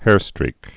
(hârstrēk)